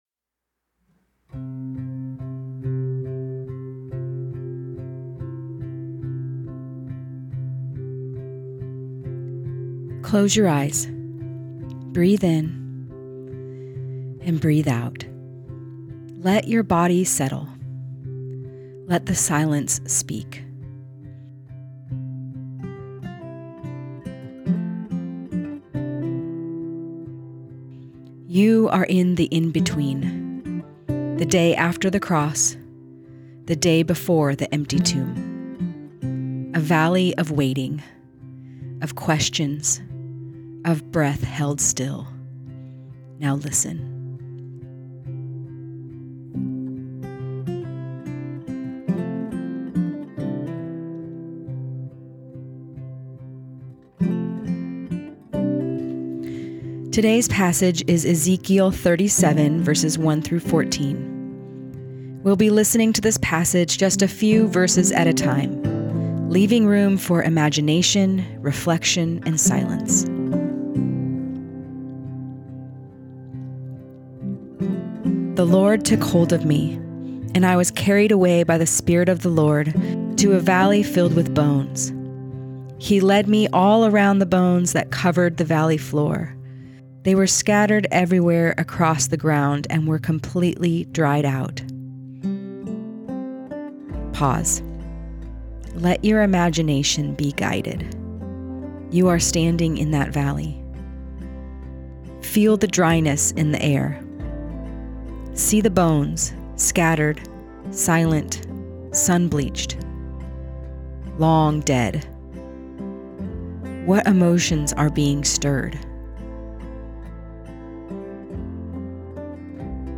Listen and Pray through Ezekiel 37:1-14
lent-26-ezekiel37.mp3